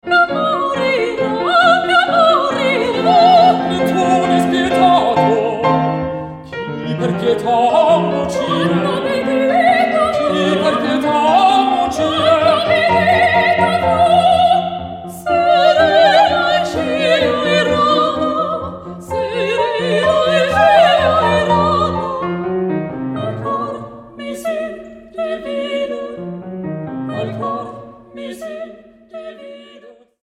Audiobranding IFG IFG_Jingle
Mobile Recording MDR Choraufnahme